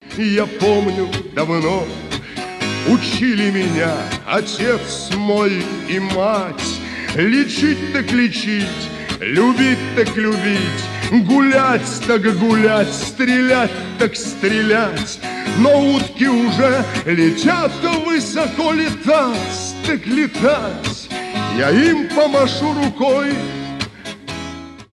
гитара , шансон , русские , акустика , 80-е